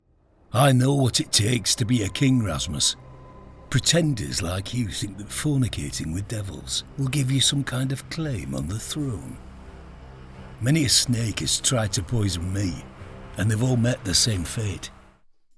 Character voice Sean bean
Rich resonant and engaging voice with the ability to add impersonations such as Russell Crowe, Sean Connery, Ewan Macgregor, John Hurt, Ray Winstone and many more.